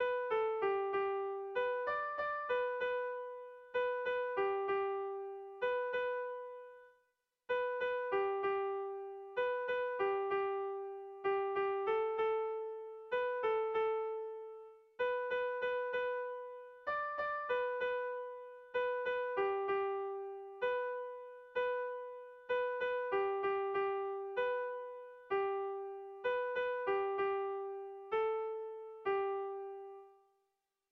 Dantzakoa
Zortziko txikia (hg) / Lau puntuko txikia (ip)
A1A2A3A2